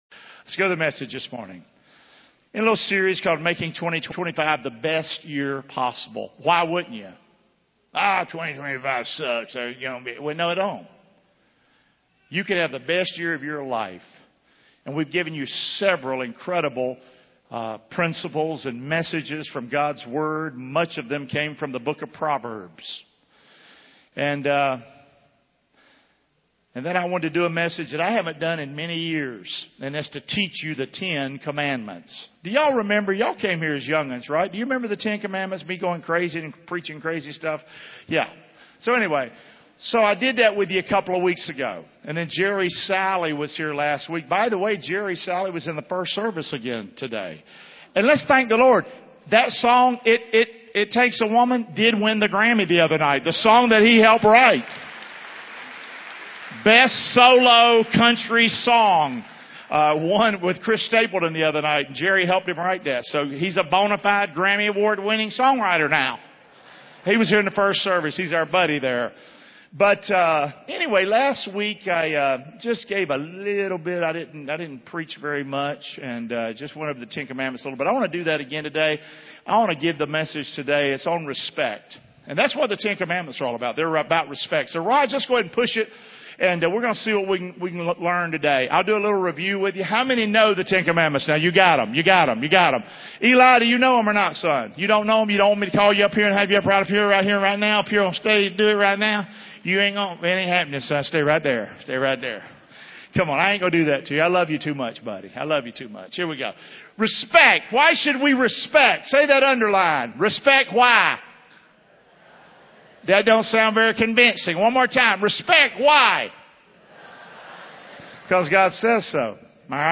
The latest sermons of Fellowship Church in Englewood, FL.